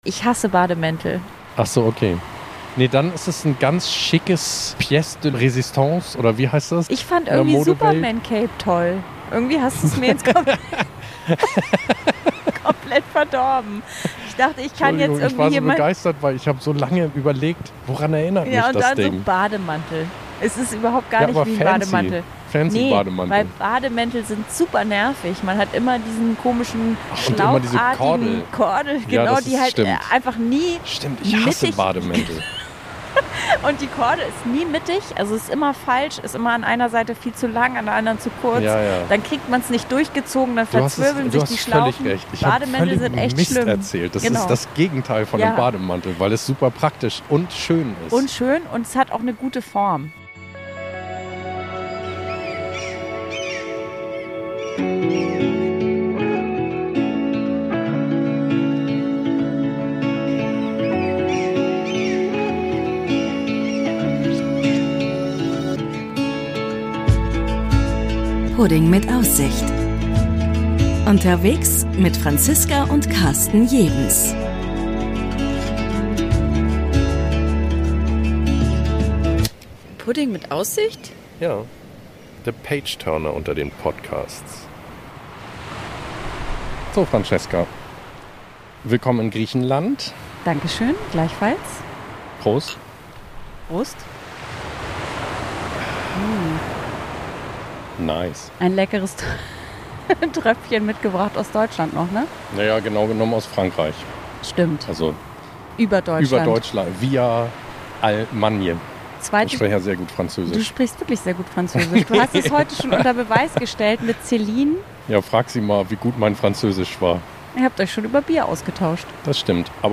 Natürlich bauen wir da sofort das Champagnertischchen auf, holen den eisgekühlten Crémant aus der Kühlbox und stöpseln die Mikros ein, um mit Blick aufs Meer die erste Folge der zweiten Staffel aufzunehmen.